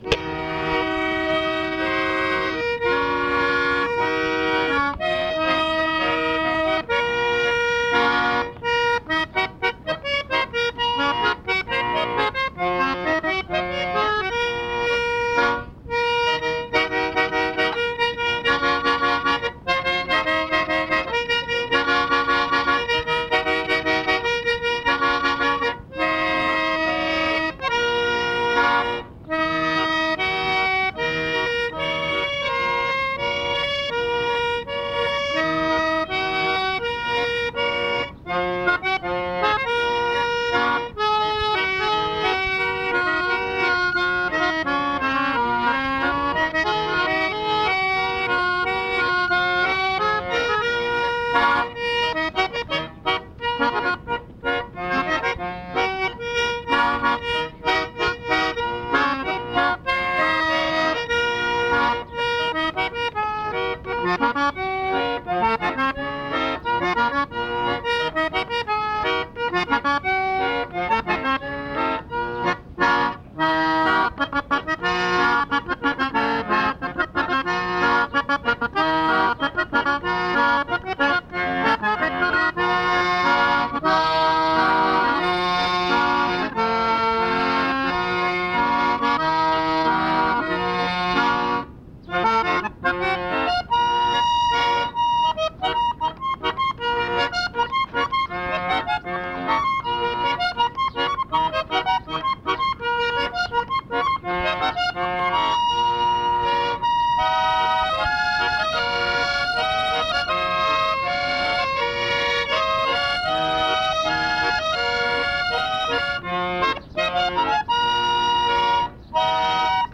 ЖанрІнструментальна музика
гармонь